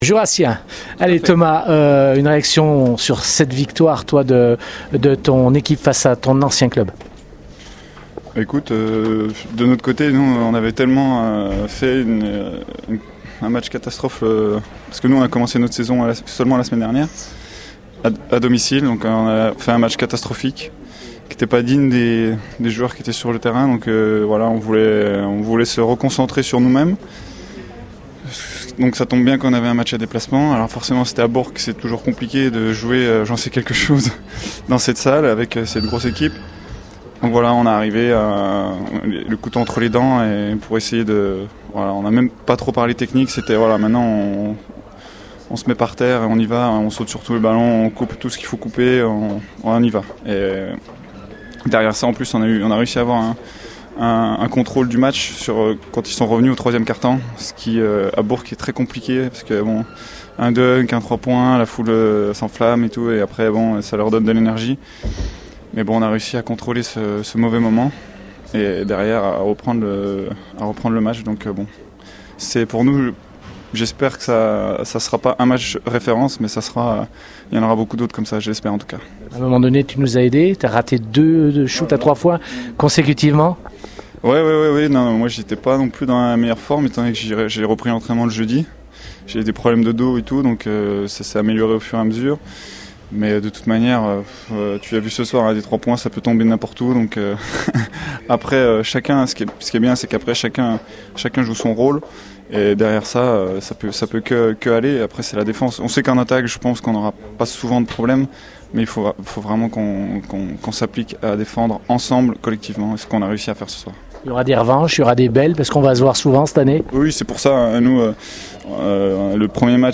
On écoute les réactions au micro